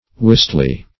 wistly - definition of wistly - synonyms, pronunciation, spelling from Free Dictionary Search Result for " wistly" : The Collaborative International Dictionary of English v.0.48: Wistly \Wist"ly\, adv.